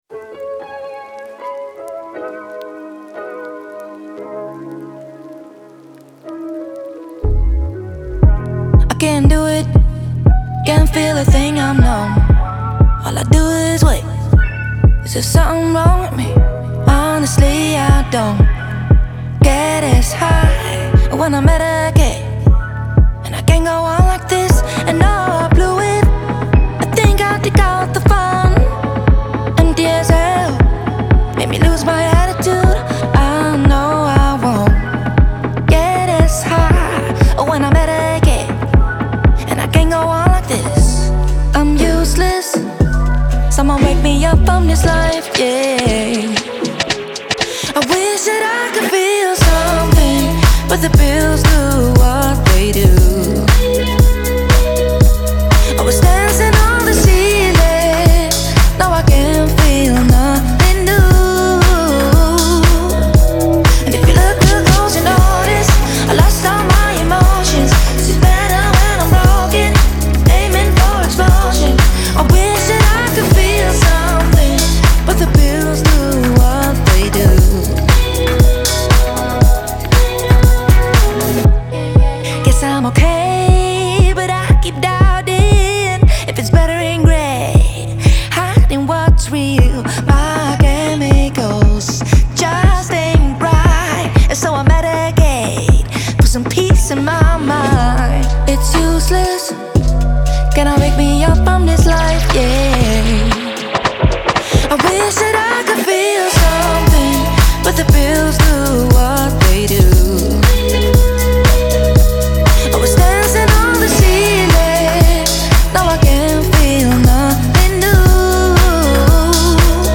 Genre : R&B